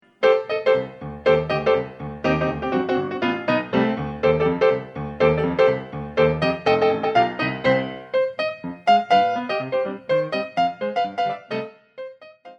concert pianist
for intermediate ballet classes.